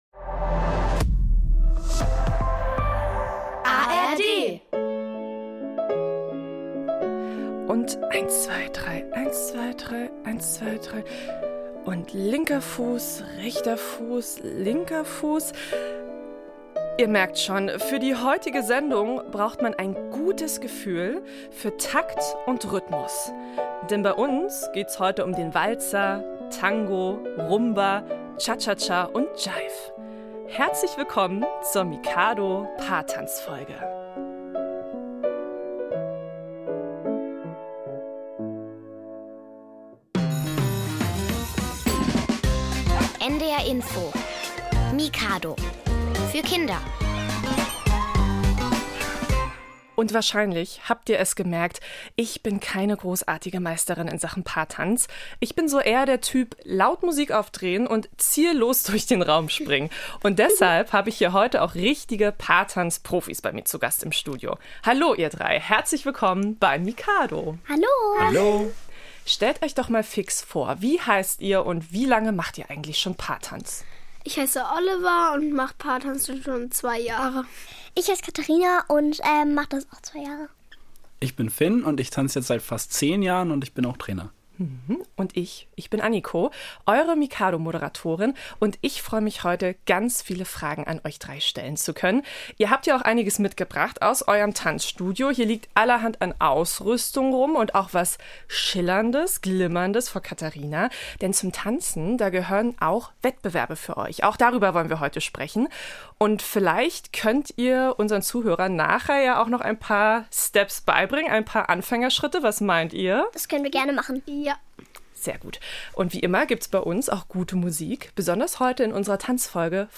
Aktuelle Themen mit Aha-Effekt - zum Lachen, Lernen und Weitersagen. Bei uns kommen Kinder und Experten zu Wort, es gibt Rätsel, Witze, Reportagen, Buch- und Basteltipps, Experimente und Musik.